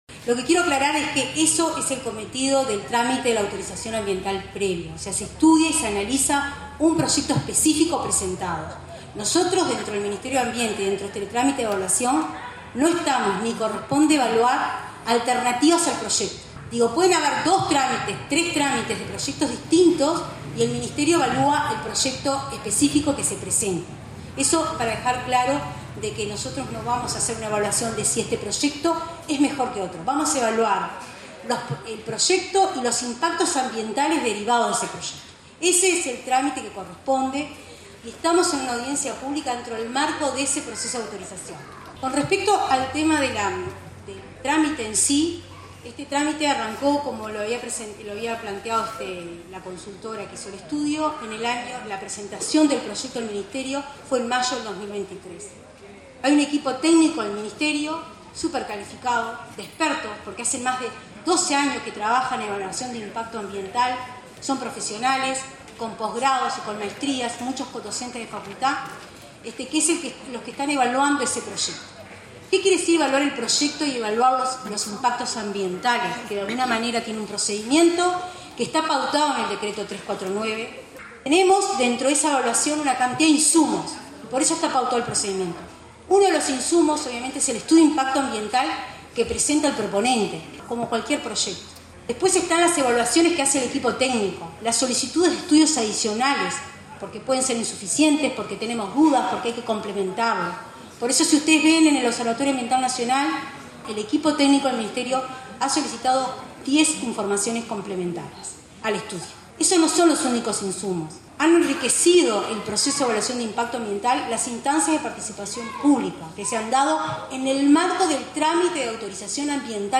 Como estaba previsto, desde las últimas horas de la tarde de ayer, se realizó, convocada por el Ministerio de Ambiente,  en el Club San Rafael, de Rafael Perazza,  la «Audiencia Pública» del «Proyecto Arazatí», instancia en la que participaron las máximas autoridades del Ministerio de Ambiente y OSE.